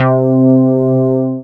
junobass2.wav